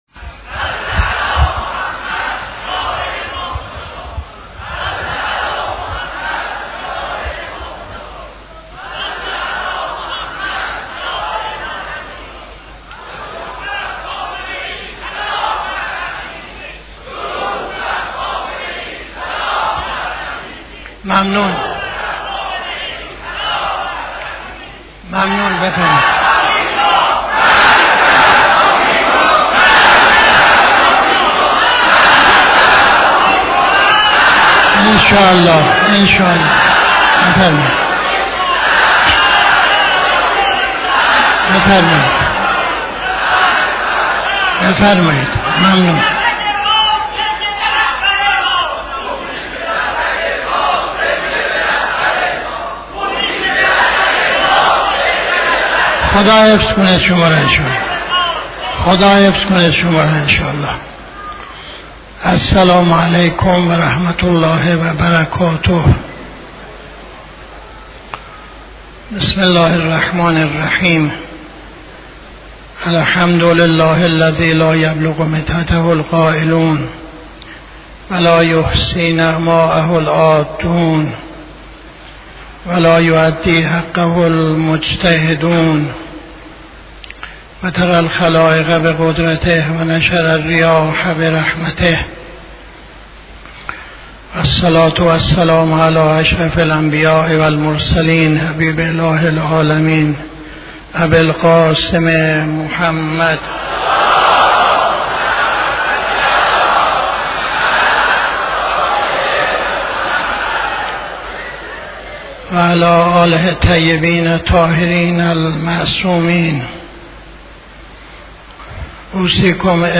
خطبه اول نماز جمعه 18-03-80